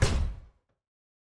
Footstep Robot Large 2_05.wav